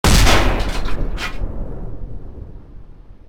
Tank_Turret2.ogg